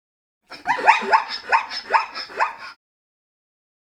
めばえ４月号 シマウマのなきごえ